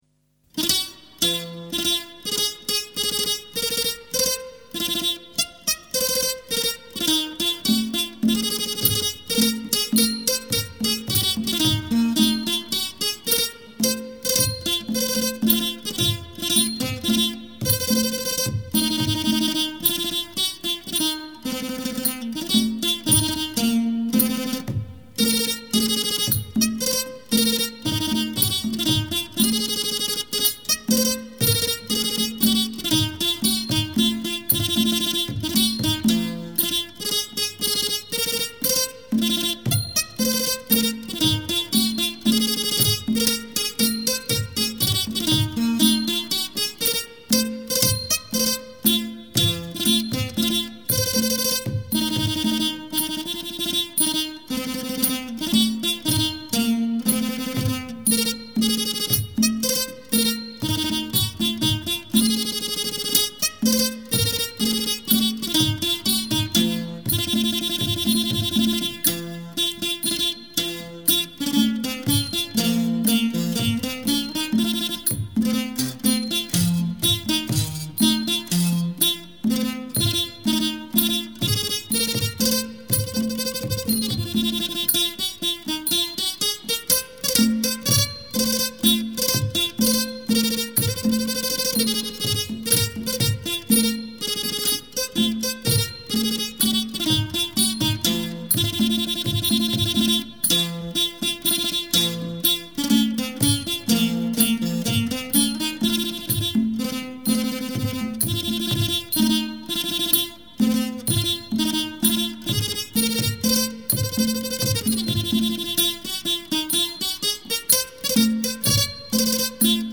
[3/5/2008]Thai Classical Music (Instrumental)
Thai Classical Music